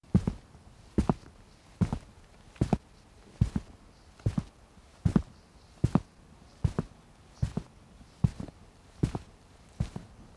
SFX_Footsteps_Stone_03_Slow.wav